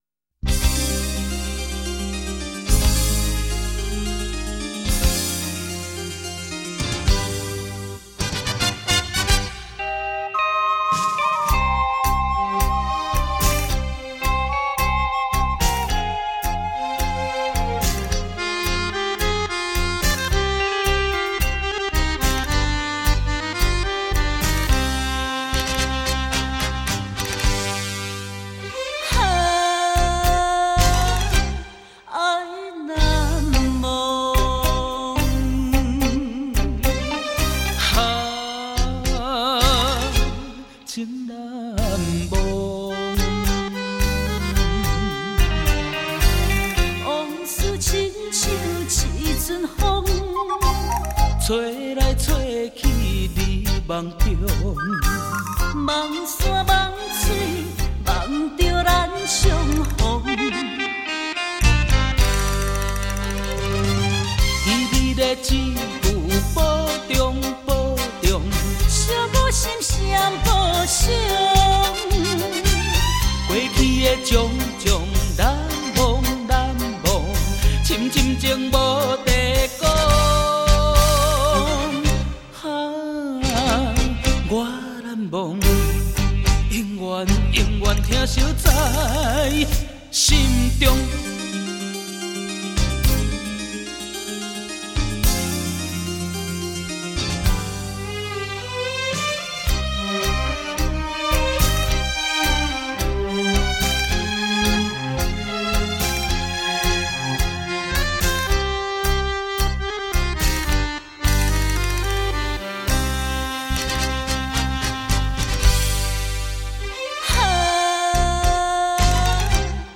怀旧的歌声